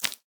Minecraft Version Minecraft Version 25w18a Latest Release | Latest Snapshot 25w18a / assets / minecraft / sounds / block / cobweb / step2.ogg Compare With Compare With Latest Release | Latest Snapshot
step2.ogg